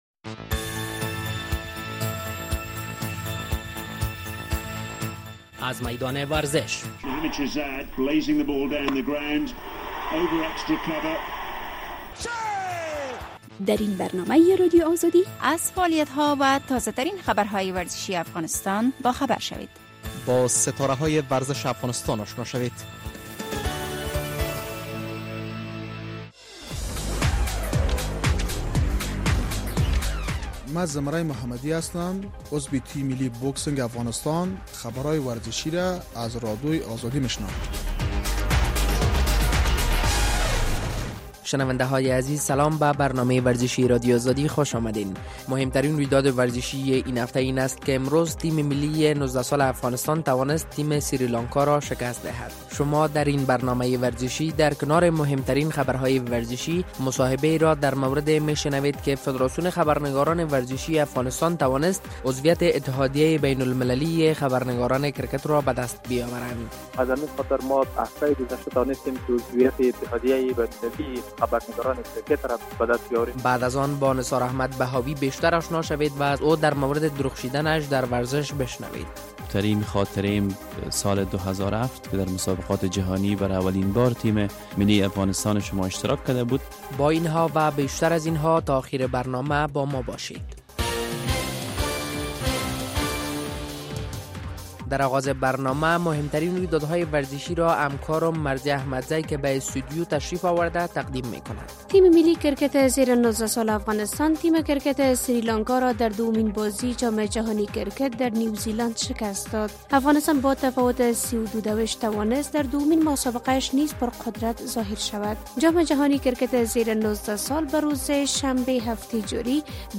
شنونده‌گان عزیز شما درین برنامۀ ورزشی در نخست تازه‌ترین خبرهای ورزشی و بعد از آن مصاحبه را در مورد چگونگی سال ۲۰۱۸ برای ...